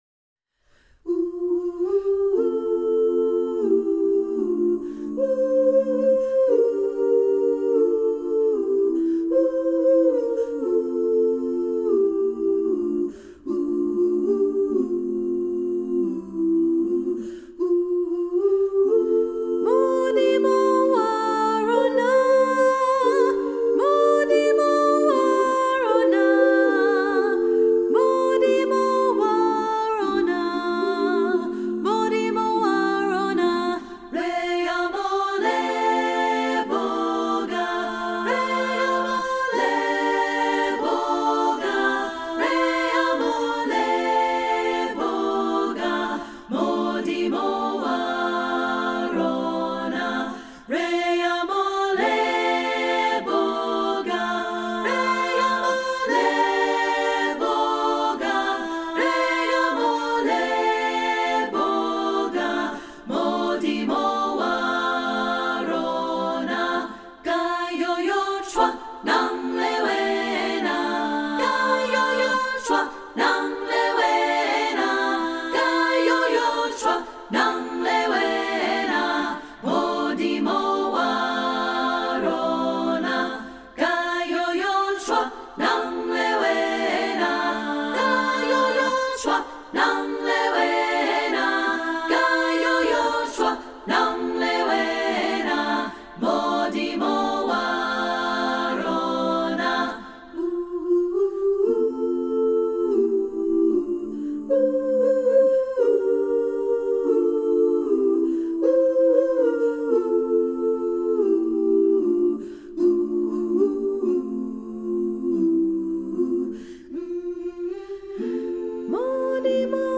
Composer: Botswana Hymn
Voicing: SSA a cappella